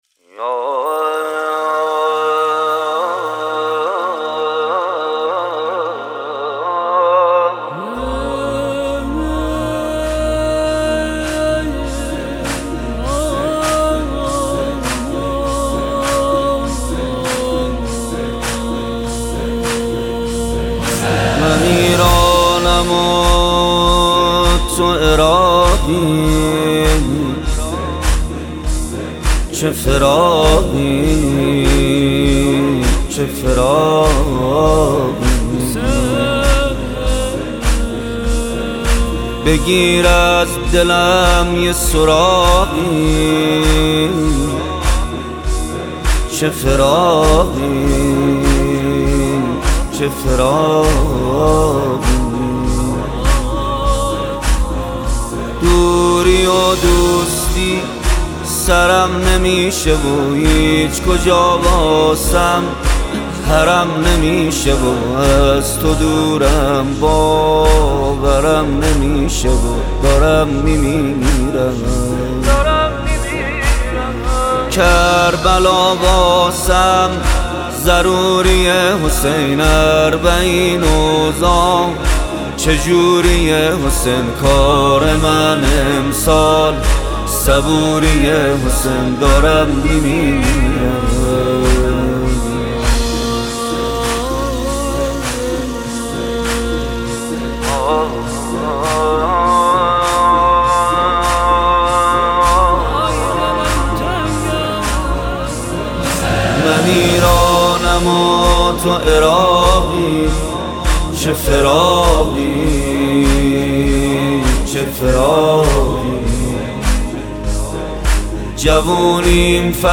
نسخه مداحی استودیویی